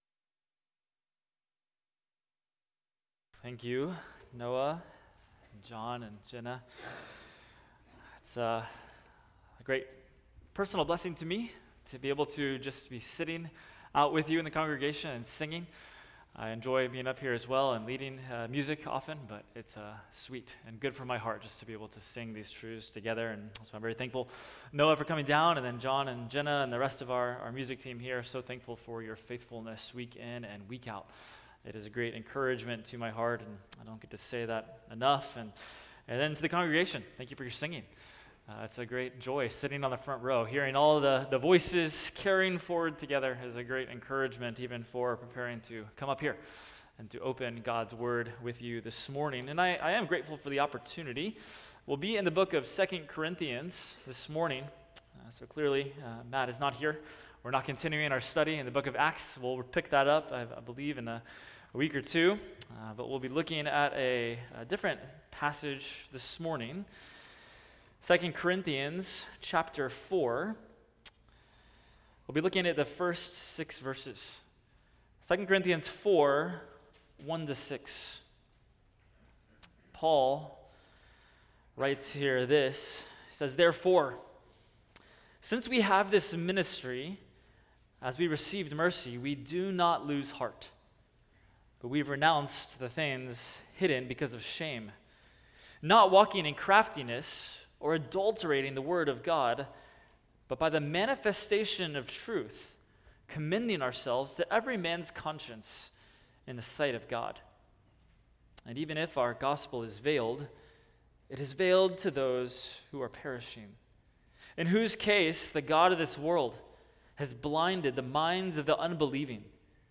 Expository Preaching from 2 Corinthians – 2 Corinthians 4:1-6 – A Ministry that Endures